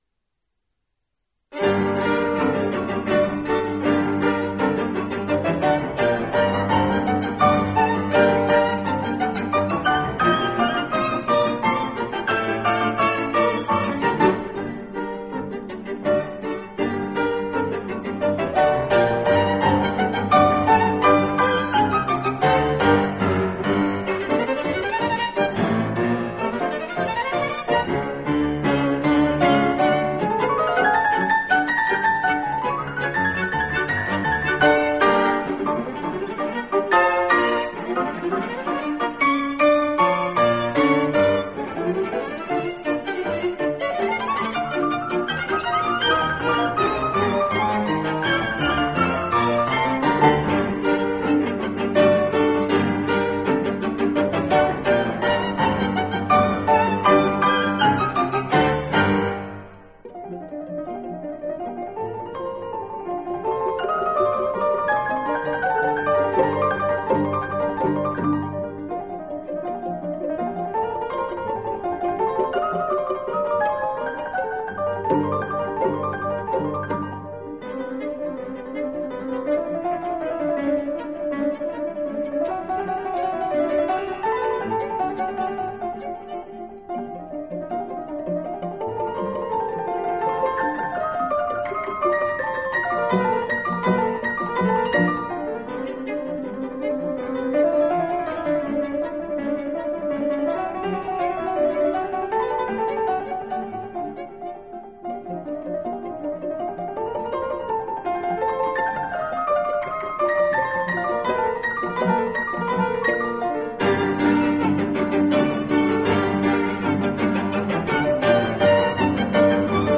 音樂類型：古典音樂
Klierquartett Nr. 1 g-moll op. 25